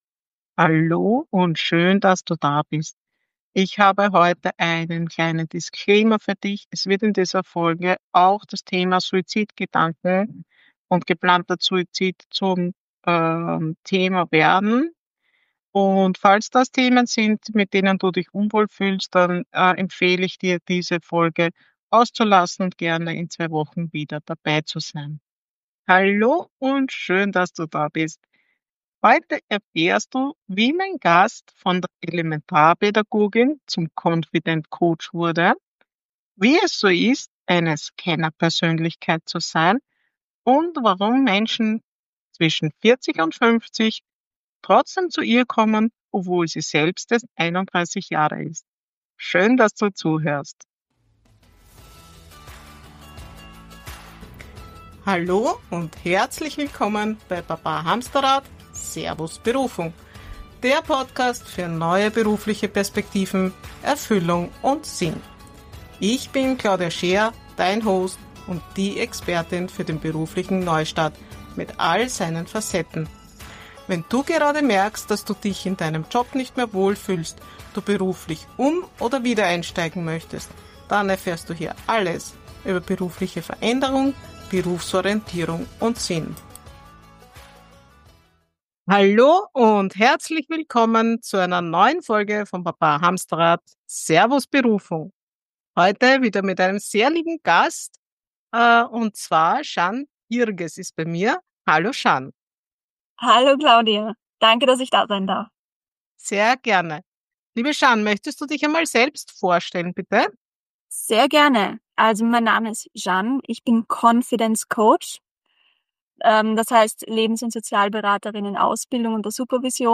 Ein Gespräch über Mut, Vielfalt und die Kraft, seinen eigenen Weg zu gehen.